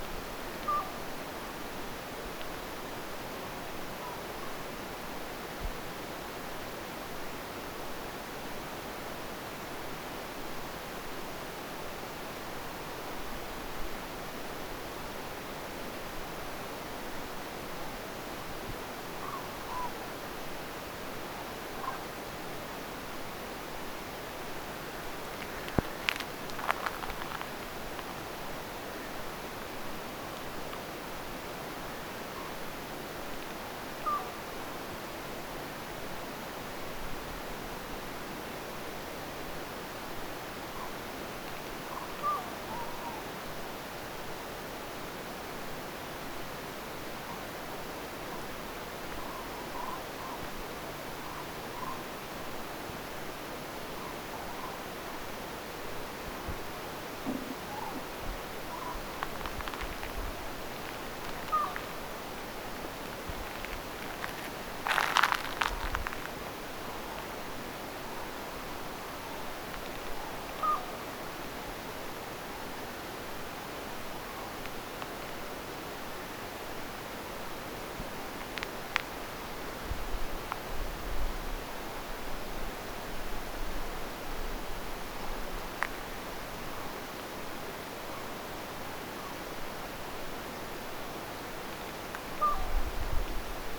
ei, kyllä se sittenkin on varis?
paitsi että on liian matala sekä aivan hieman epäpuhdas.
ehka_varis_matkii_kylapollosta_kylapollonen_jopa_mahdollinen_1.mp3